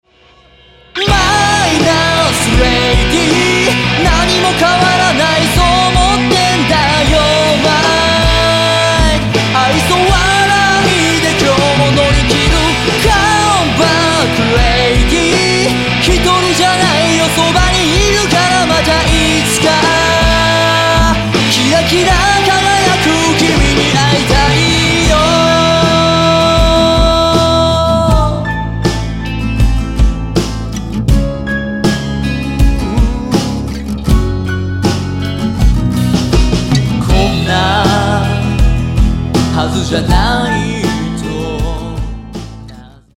エンターテイメント・パーティ・ハードロックスターバンド!!